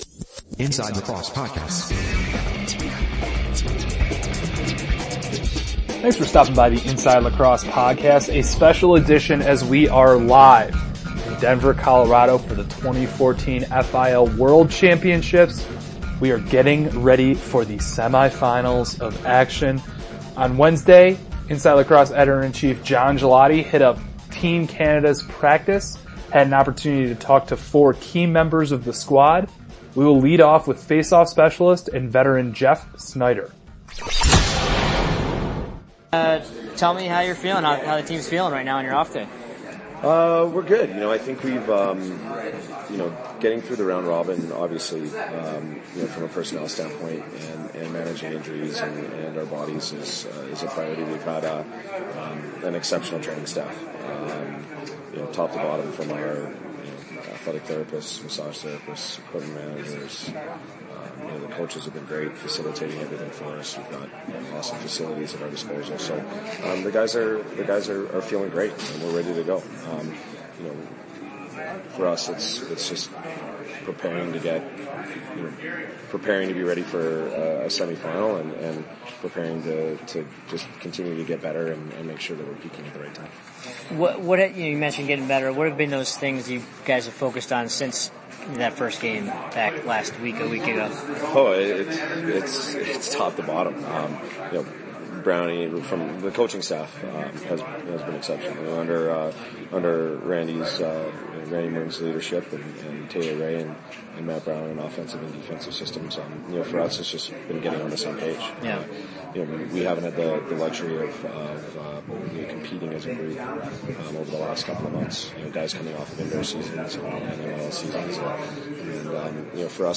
talked to four key members of Team Canada on their off day prior to the FIL World Championship semifinal